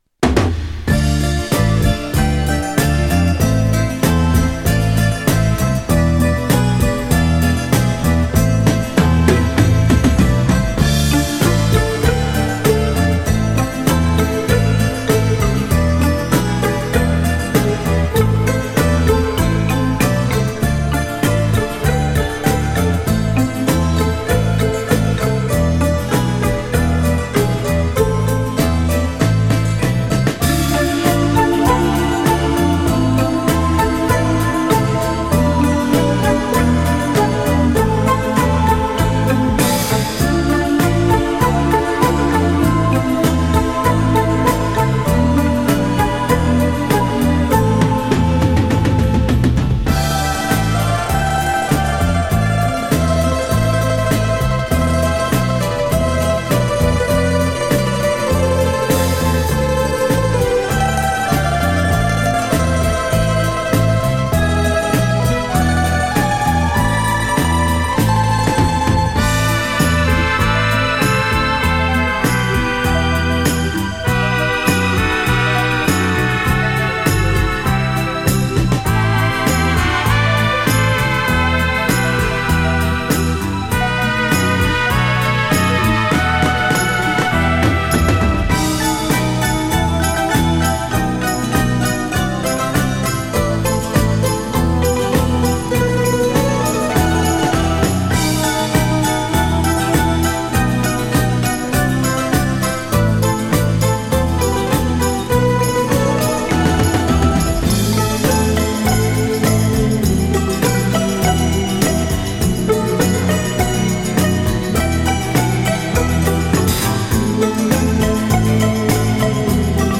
Жанр: Folk
Источник винил, взято из интернета